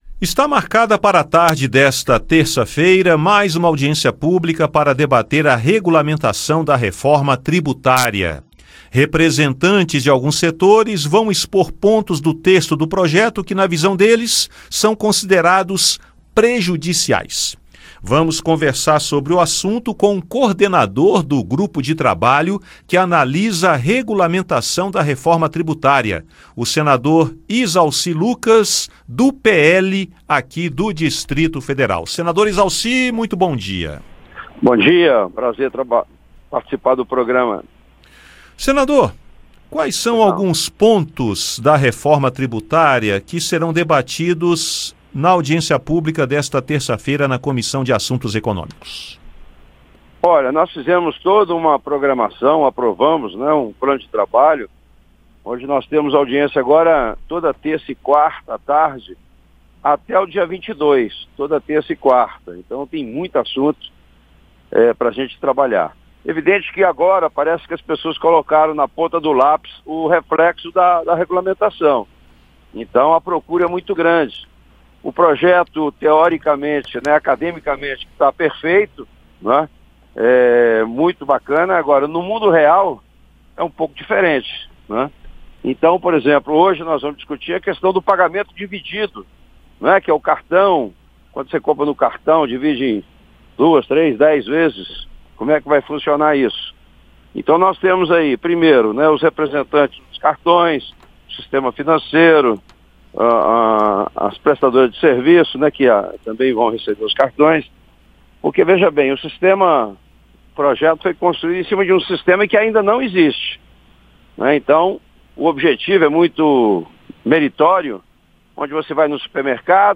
O grupo de trabalho que analisa a proposta de regulamentação da reforma tributária (PLP 68/2024) faz audiência pública pública na Comissão de Assuntos Econômicos (CAE) na tarde desta terça-feira (20). O coordenador do grupo, senador Izalci Lucas (PL-DF), destaca pontos que serão debatidos (entre eles, alguns considerados prejudiciais), as possíveis alterações do texto aprovado na Câmara dos Deputados e a expectativa de manter o cronograma previsto para votação no Senado sem prejuízo do calendário eleitoral.